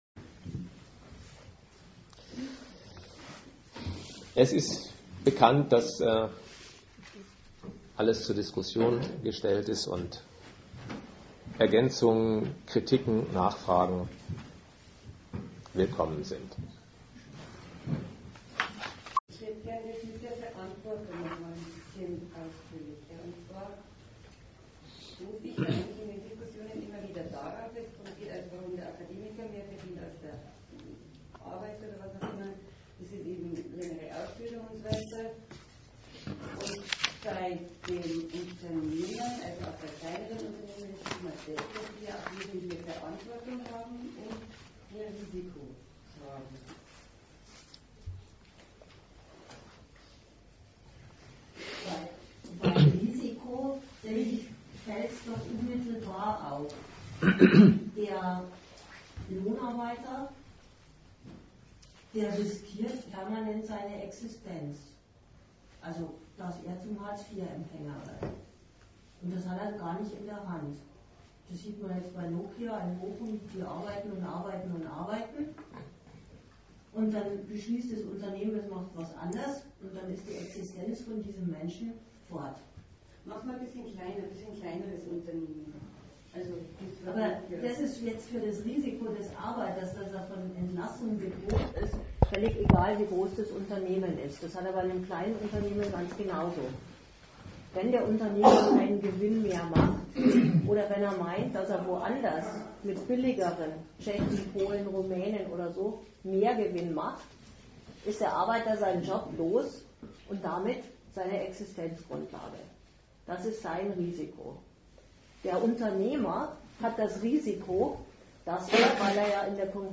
Gliederung:Teil 1: MindestlohnTeil 2: ManagergehälterTeil 3: Warum verdienen Manager so viel?Teil 4: Diskussion